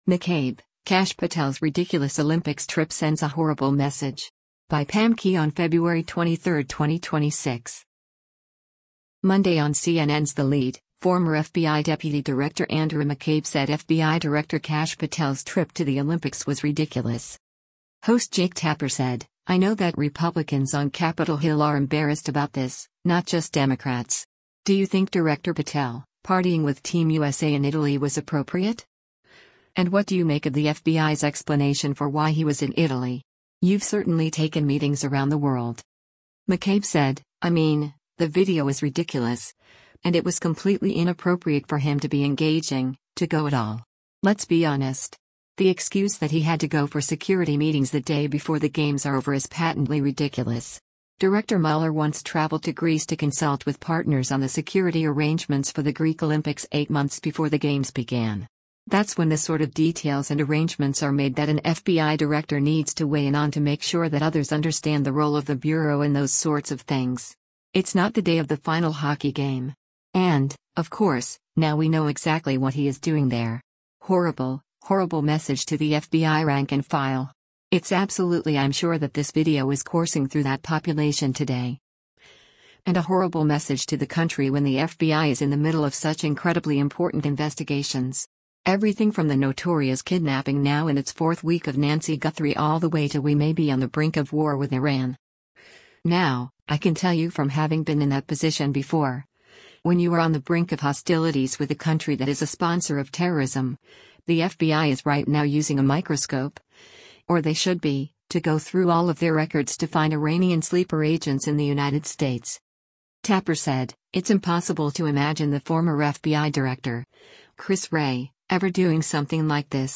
Monday on CNN’s “The Lead,” former FBI Deputy Director Andrew McCabe said FBI Director Kash Patel’s trip to the  Olympics was “ridiculous.”